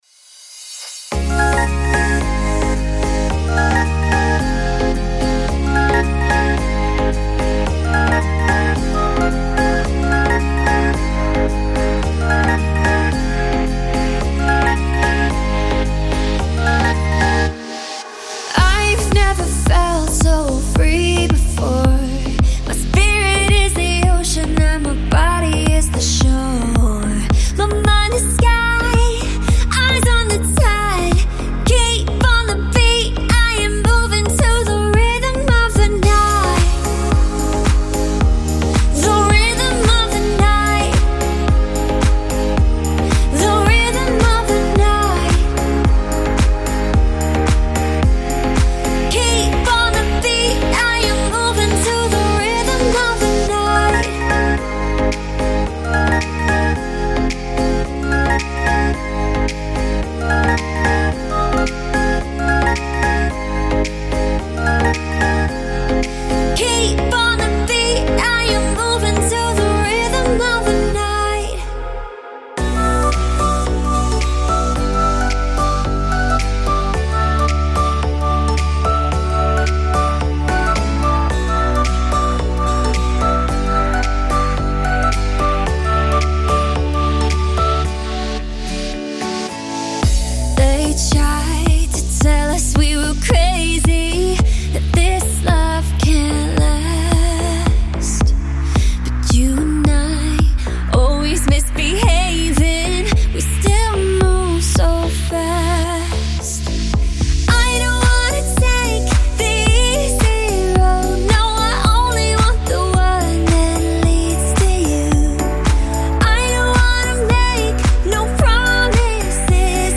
如果您要制作流行音乐或Tropical House音乐，那么您会喜欢这个样本包。
每个套件都包含干声和湿声，对于想要简单拖放的制作人，“湿”人声包含音频演示中听到的所有效果处理。
声音记录在100和110bpm以下进行。
请注意：不包括鼓和FX
·5个潮湿的Acapella声乐
·42个乐器循环（钢琴，低音，打击垫，旋律，吉他）
·根音键：C，C＃，D，B